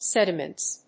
/ˈsɛdʌmʌnts(米国英語), ˈsedʌmʌnts(英国英語)/